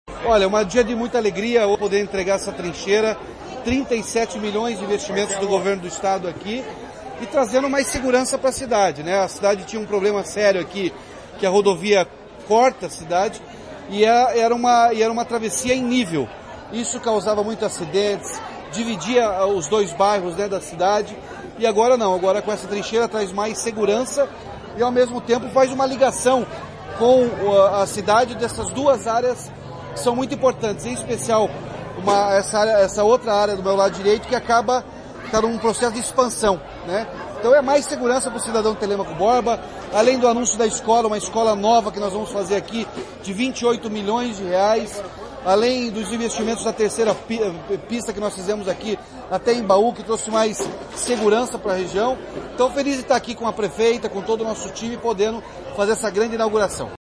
Sonora do governador Ratinho Júnior sobre trincheira na PR-160 em Telêmaco Borba